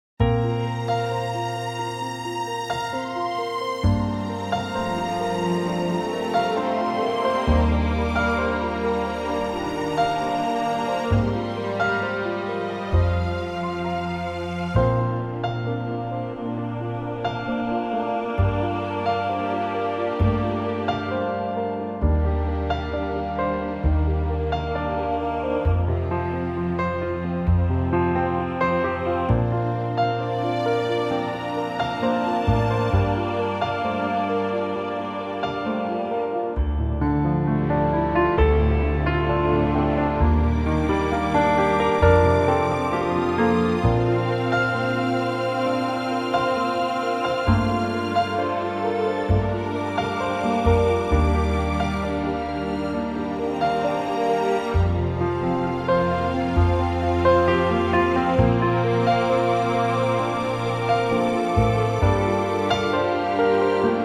key - Bb - vocal range - D to F (main theme range only)